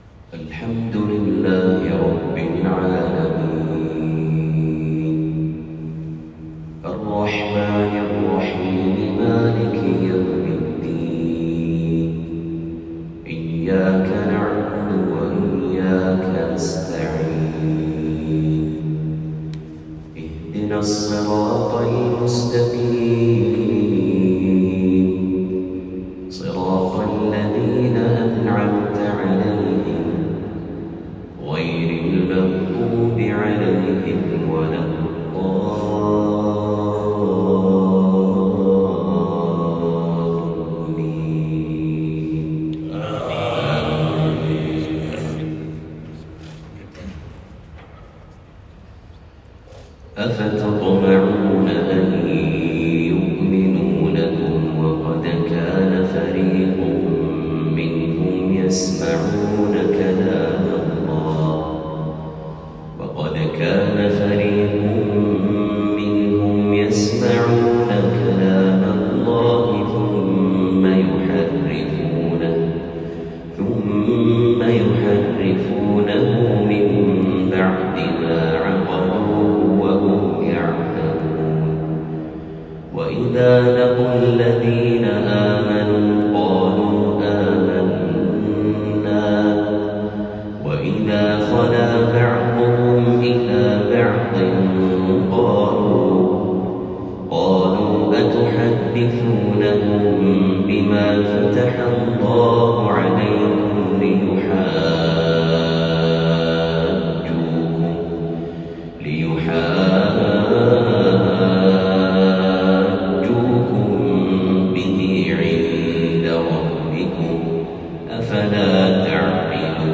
من صلاة الفجر ١٨ رمضان ١٤٤٤هـ